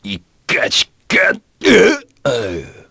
Gladi-Vox_Dead_kr.wav